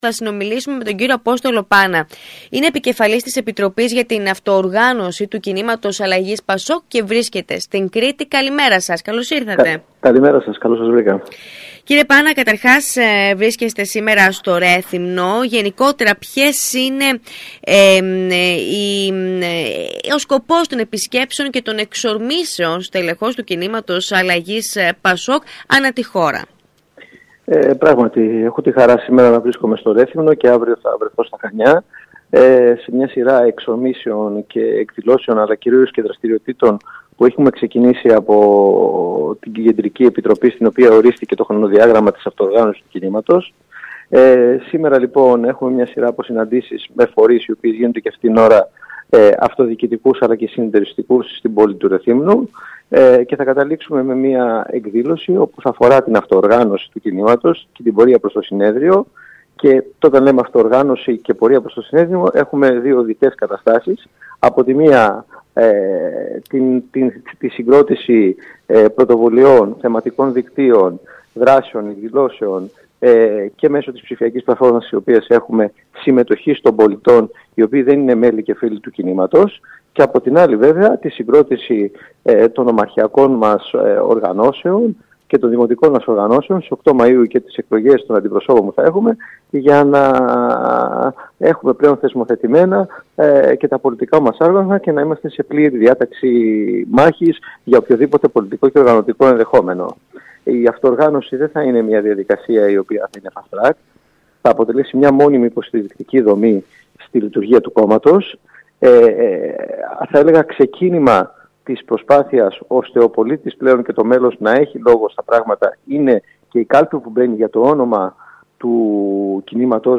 δήλωσε στον ΣΚΑΪ Κρήτης 92,1